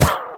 mob / witch / hurt2.ogg
hurt2.ogg